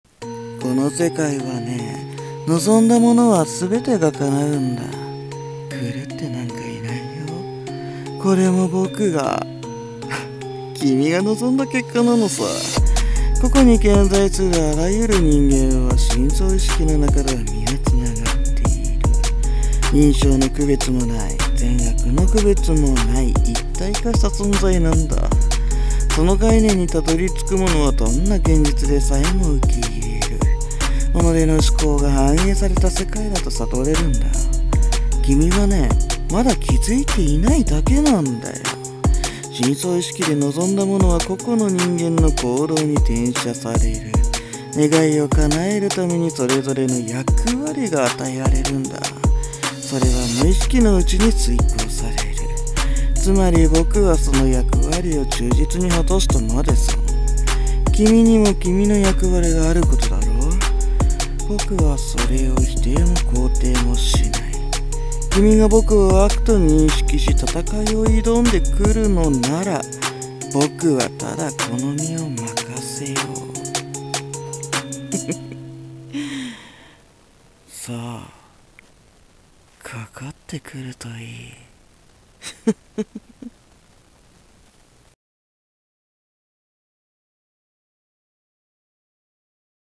【声劇台本】狂ってなんかいないよ【悪役】